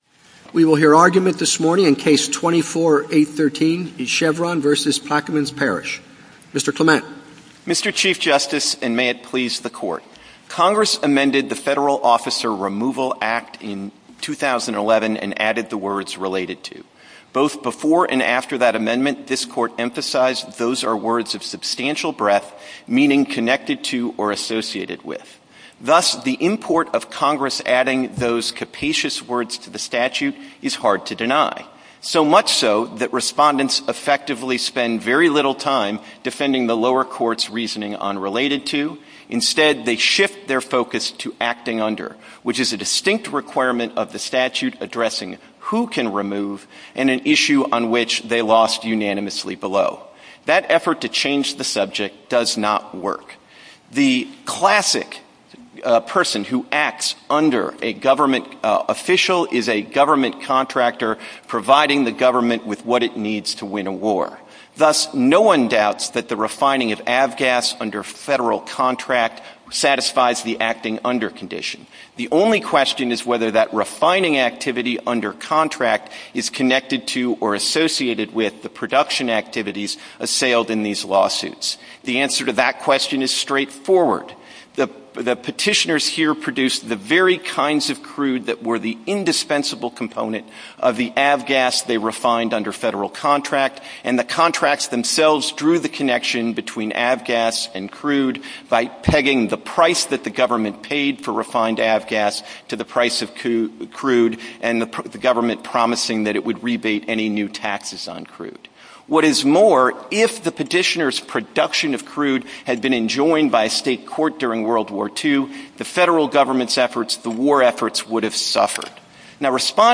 Oral Arguments for the Supreme Court of the United States Chevron USA Inc. v. Plaquemines Parish Play episode January 12 1h 17m Bookmarks Episode Description Chevron USA Inc. v. Plaquemines Parish See all episodes